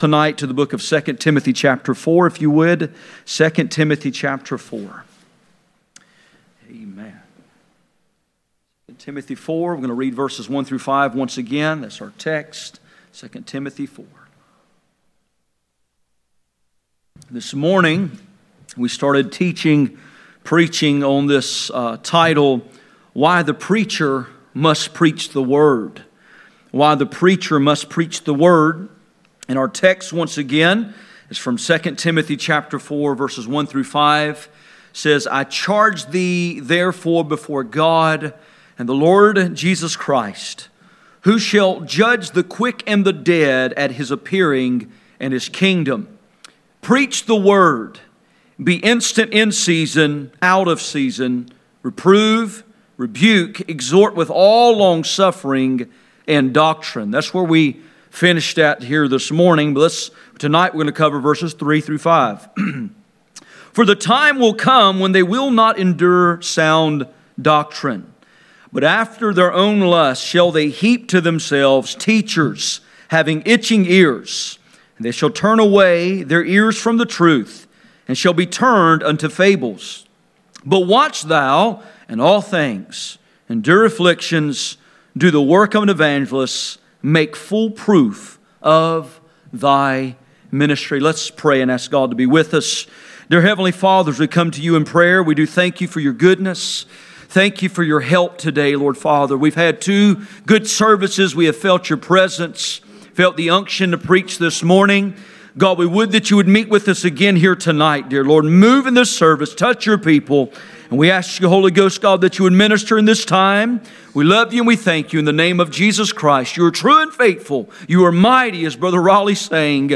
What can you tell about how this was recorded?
Passage: 2 Timothy 4:1-5 Service Type: Sunday Evening